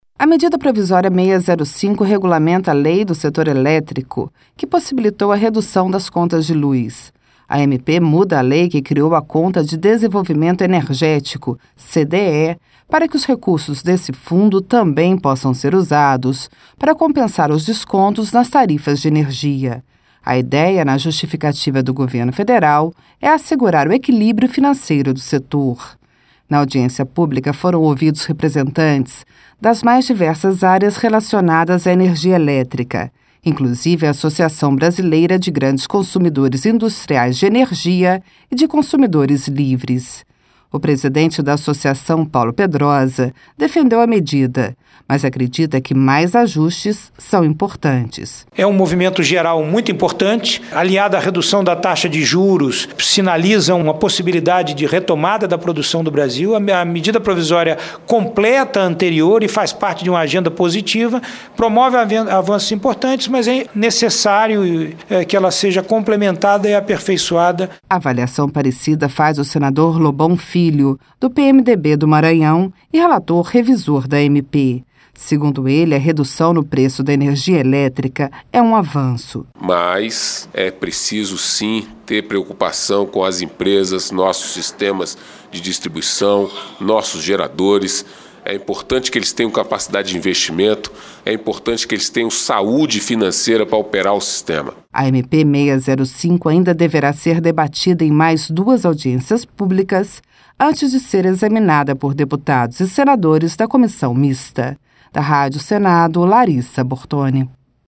Na audiência pública foram ouvidos representantes das mais diversas áreas relacionadas à energia elétrica, inclusive a Associação Brasileira de Grandes Consumidores Industriais de Energia e de Consumidores Livres.